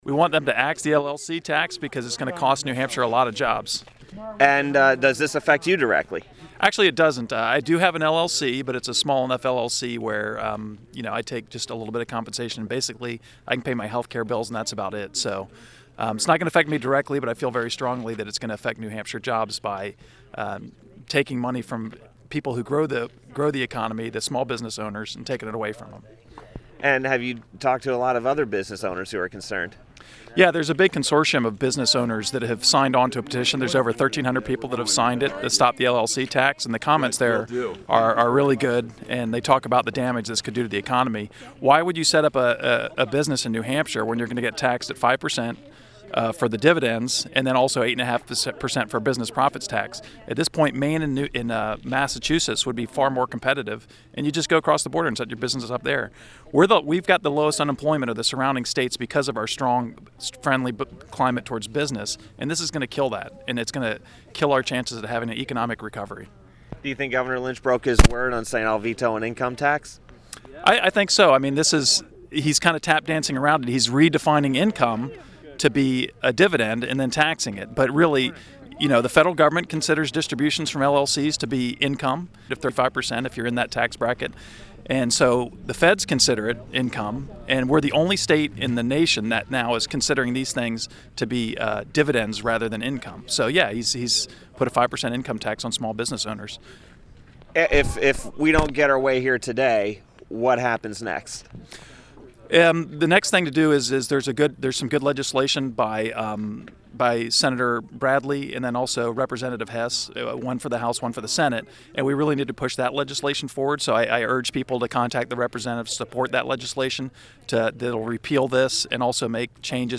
The following audio cuts are interviews I did 12/16/09 at the LLC Tax hearing in Concord and played on the show: